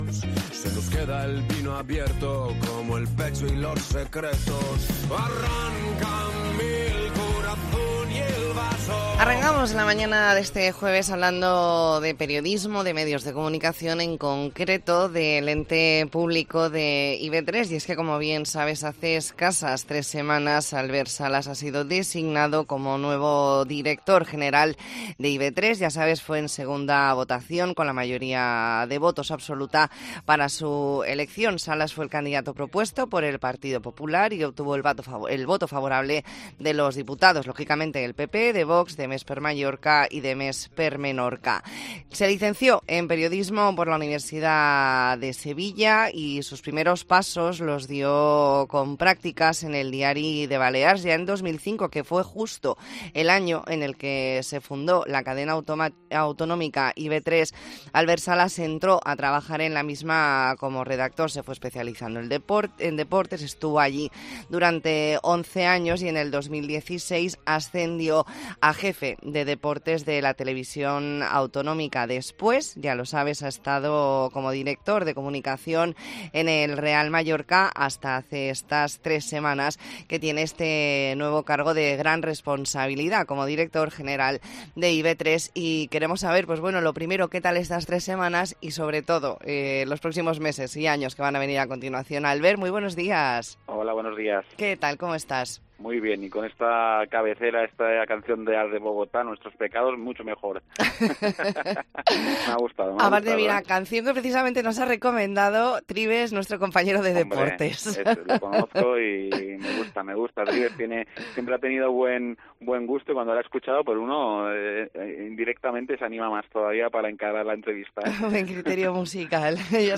Hablamos con Albert Salas, director general de IB3. Entrevista en La Mañana en COPE Más Mallorca, jueves 7 de diciembre de 2023.